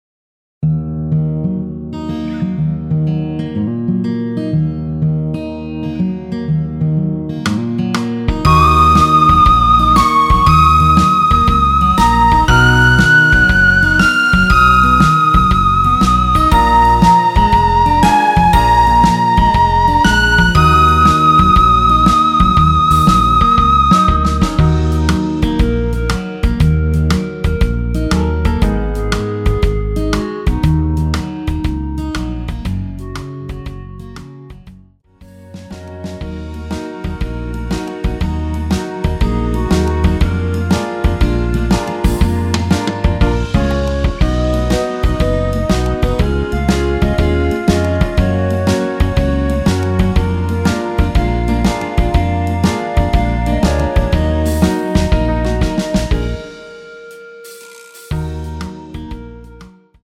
엔딩이 페이드 아웃으로 끝나서 라이브에 사용하실수 있게 엔딩을 만들어 놓았습니다.
원키에서(-1)내린 멜로디 포함된 MR입니다.
Ebm
앞부분30초, 뒷부분30초씩 편집해서 올려 드리고 있습니다.